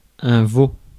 Ääntäminen
Ääntäminen France: IPA: /vo/ Paris: IPA: [vo] Paris Haettu sana löytyi näillä lähdekielillä: ranska Käännös Ääninäyte Substantiivit 1. calf UK US 2. veal US 3. calfflesh Suku: m .